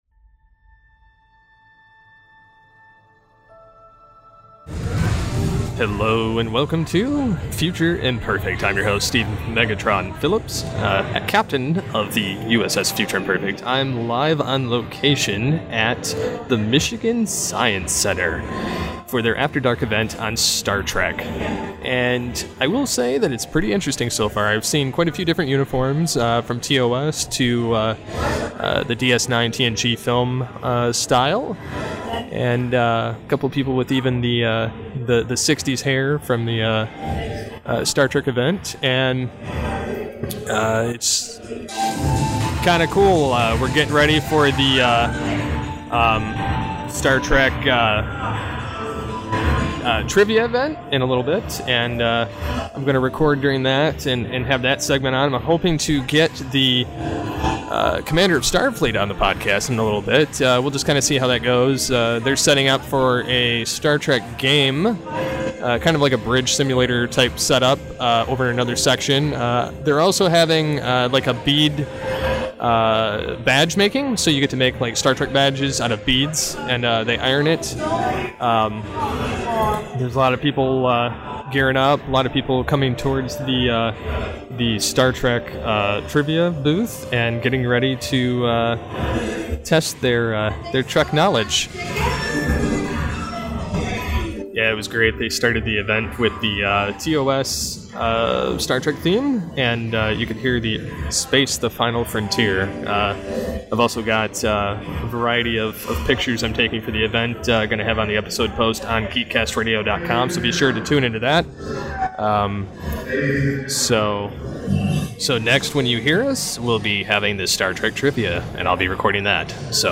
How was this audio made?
Live from the Stage of the Michigan Science Center, it's Star Trek AfterDark!